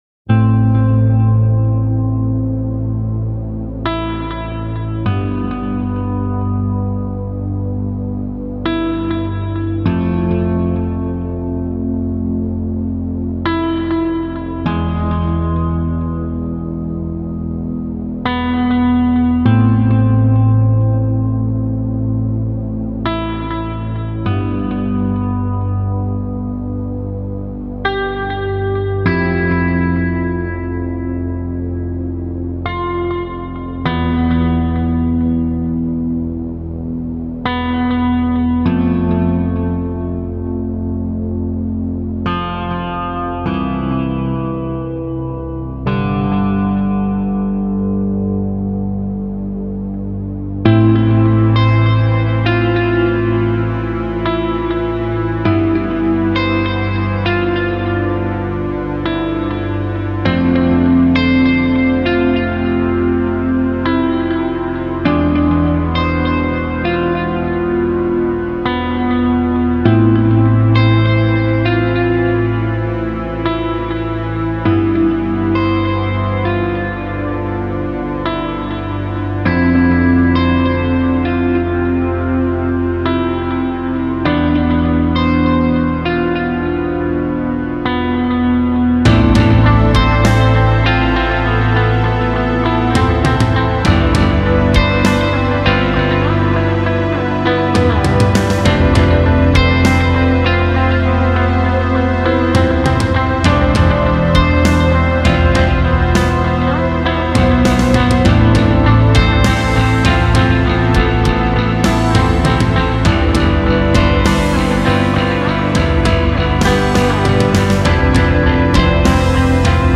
Post rock